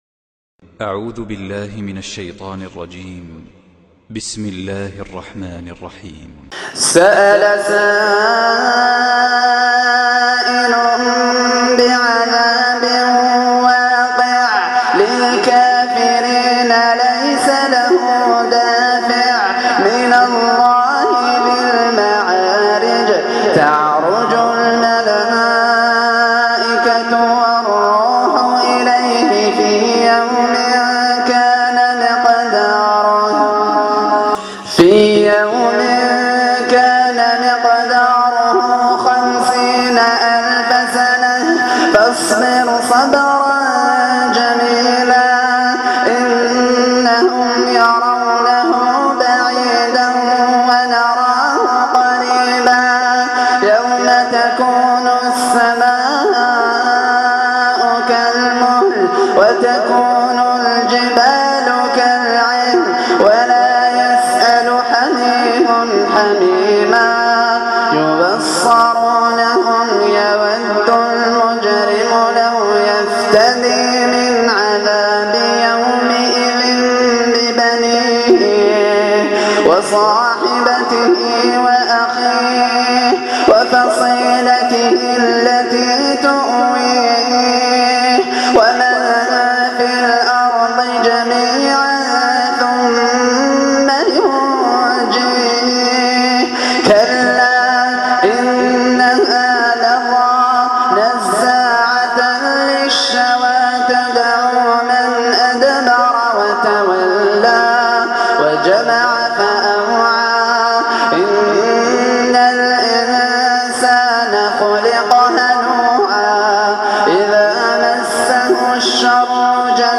تلاوة عطرة